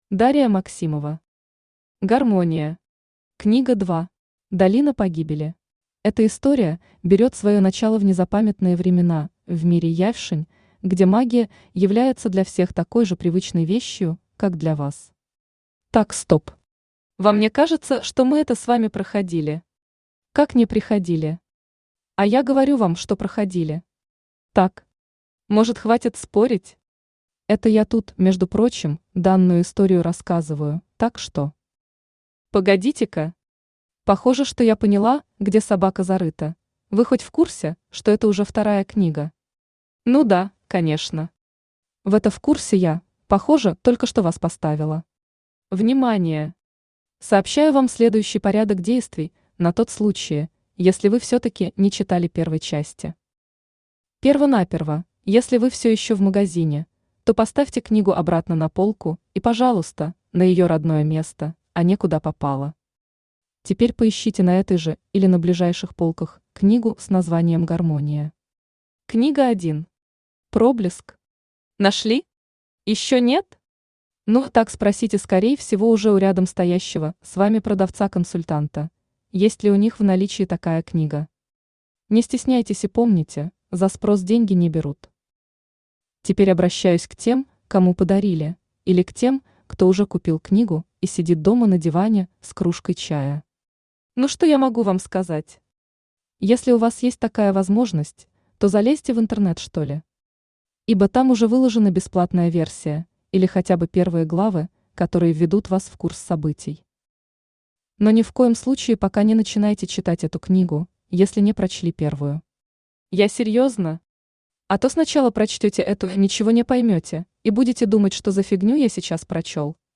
Долина Погибели Автор Дария Максимова Читает аудиокнигу Авточтец ЛитРес.